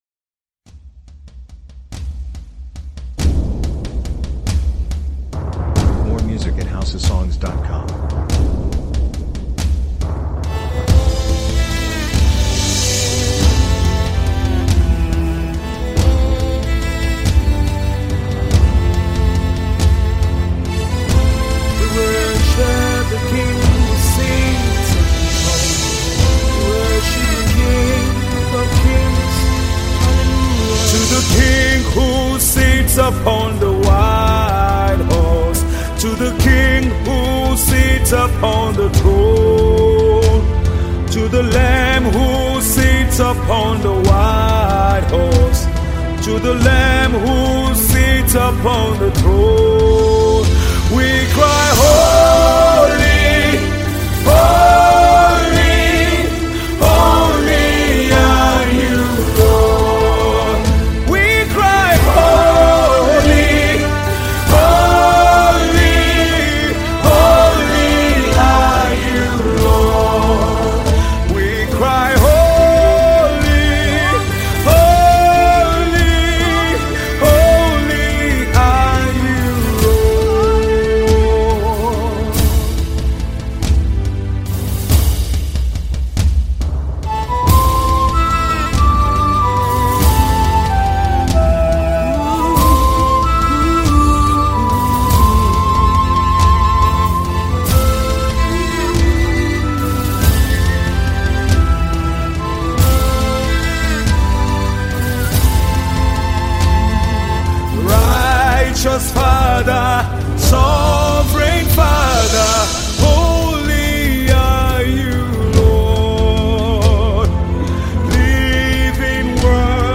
encouraging, uplifts the spirit and soul
Tiv Song